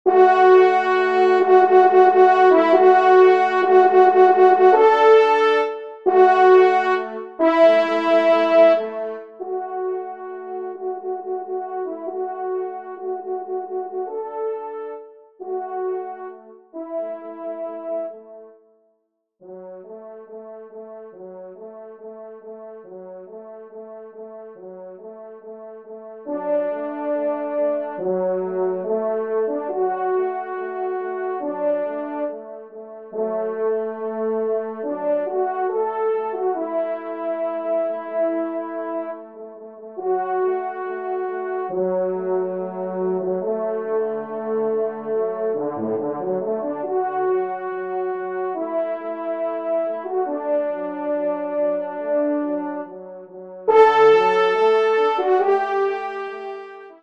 Genre :  Divertissement pour Trompes ou Cors en Ré
1e Trompe